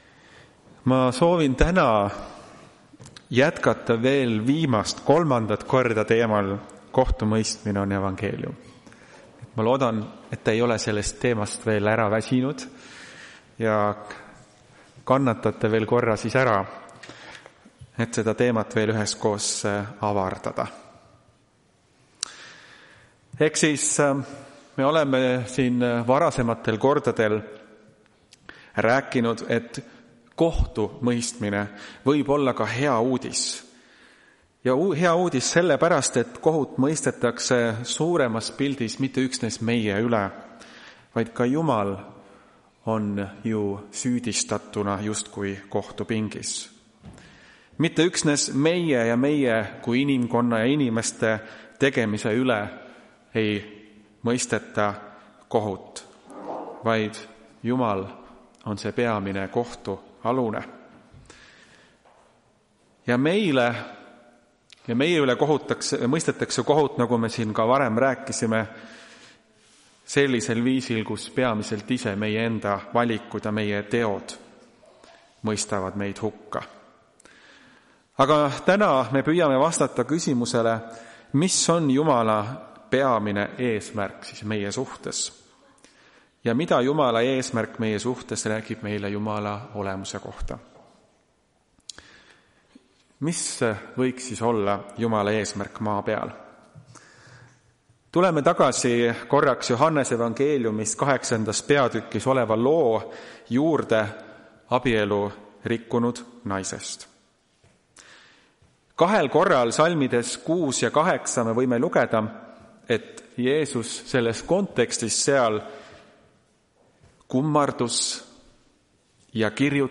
Tartu adventkoguduse 15.11.2025 teenistuse jutluse helisalvestis.